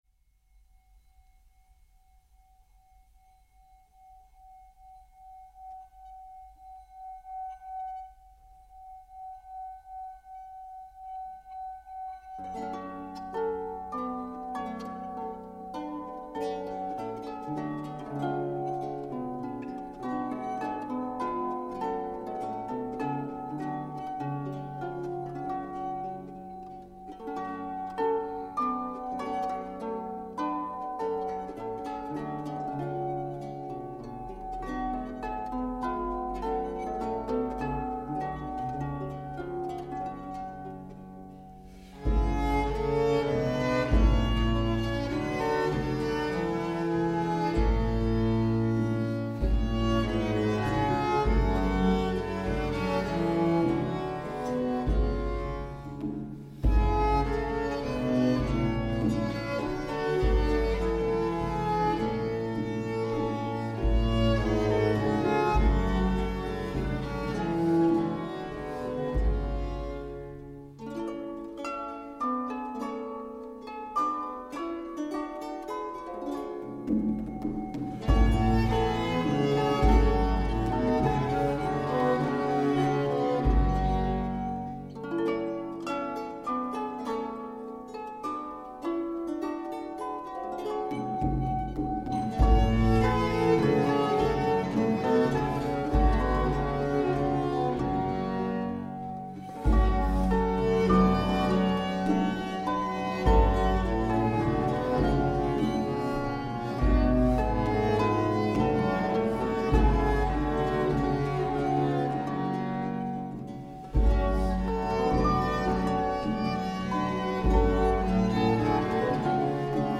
Galliard
~1500 - ~1600 (Renaissance)
Group: Dance
Renaissance dance and music popular all over Europe in the 16th century.